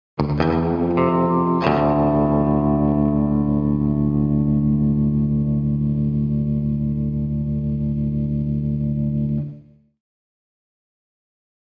Electric Guitar Twang - Texas Melody 2, (Slide Guitar)